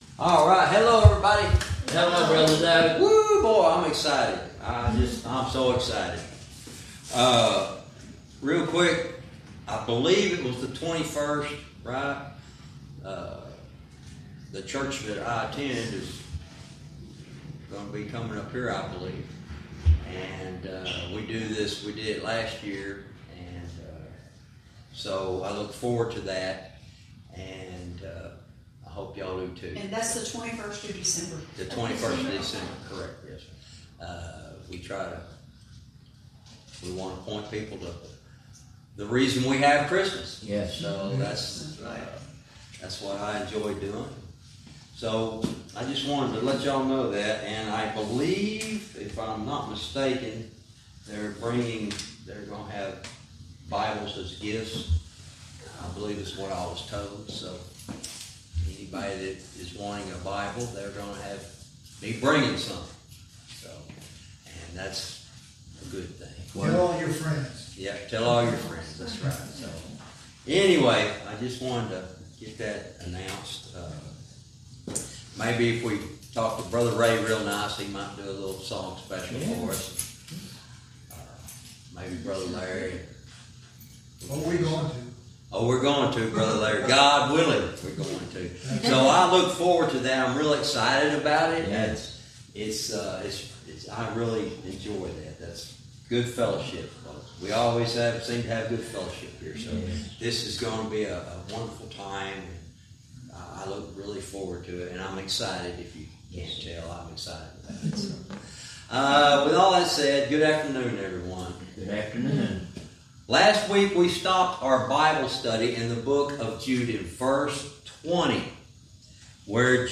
Verse by verse teaching - Jude lesson 88 verse 20